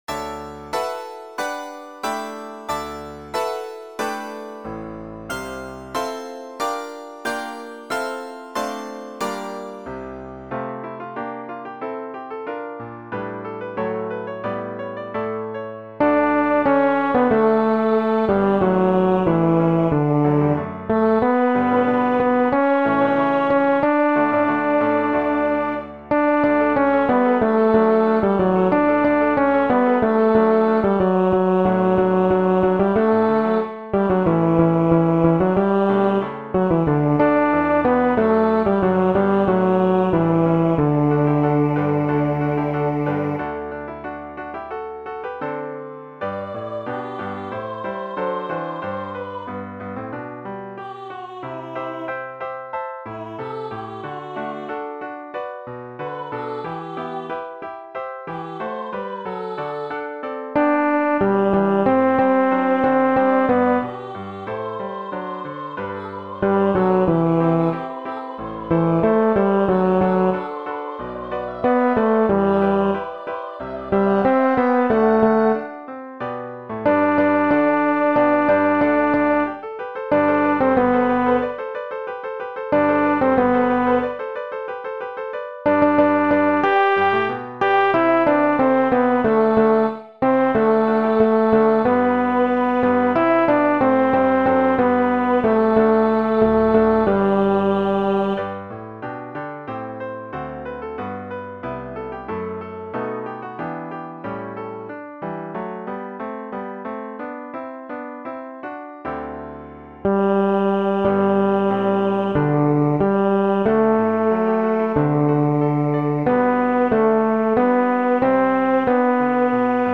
テノール・バス（フレットレスバス音）